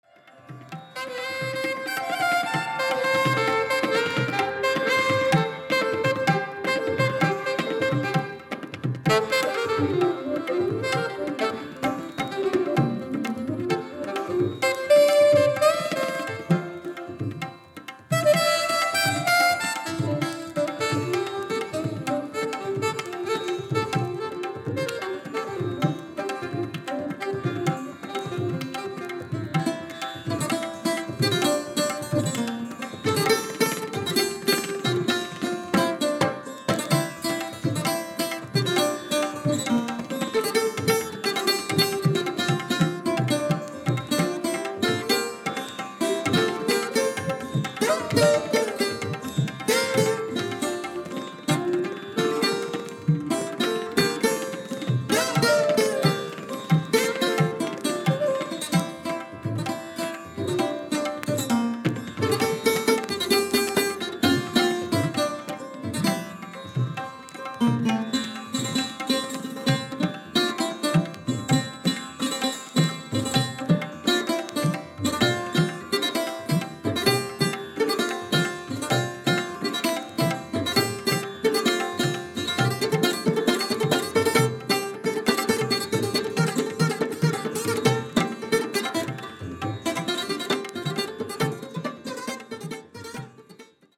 Alto Saxophone
Sarod
Tambura
Tabla